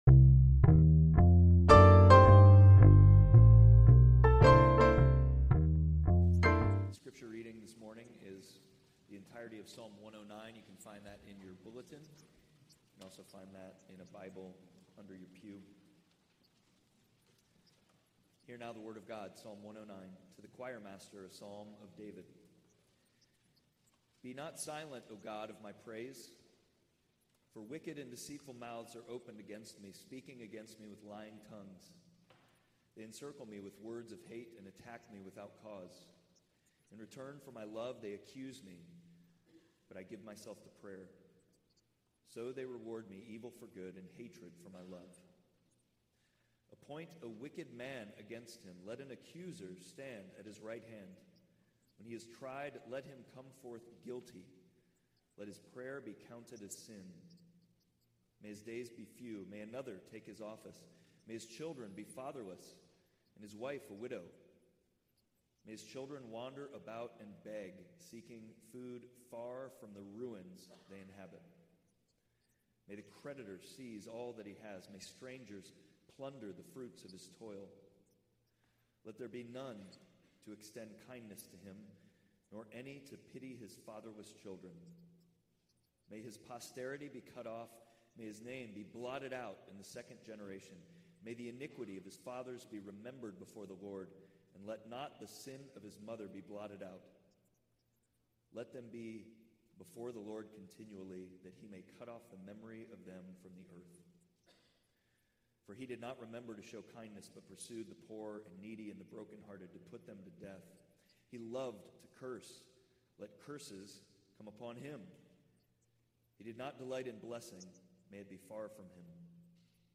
Psalm 109 Service Type: Sunday Worship We will have enemies in this life.